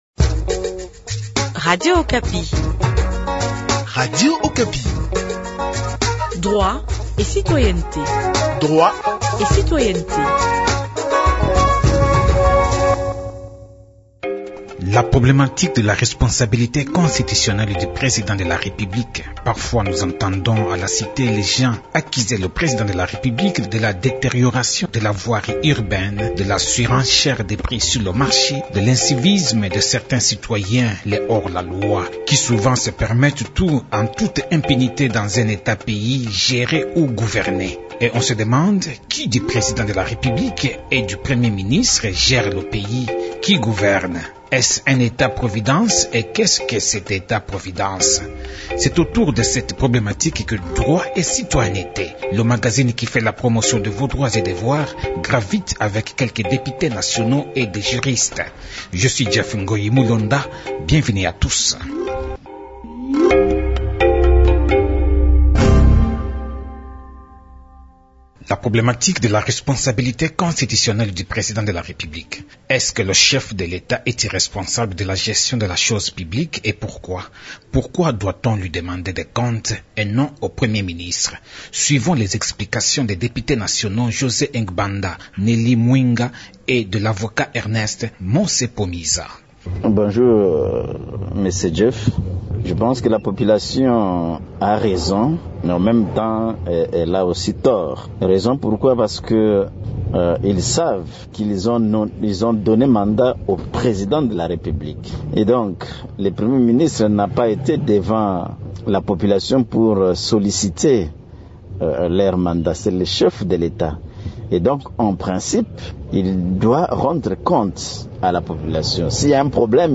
RDC : la problématique de la responsabilité constitutionnelle du président de la république expliquée par les députés, avovat et professeur.